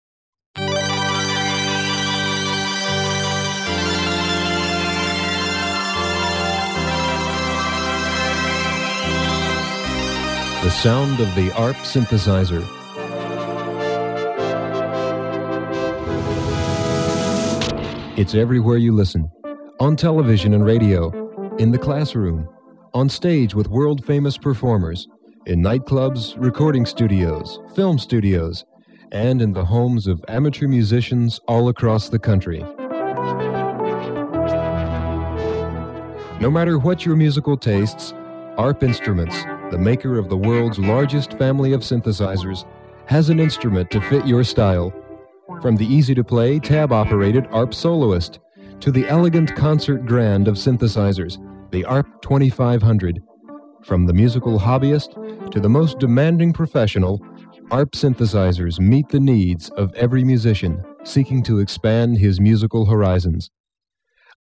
ARP 2600 Demo Tape - Intro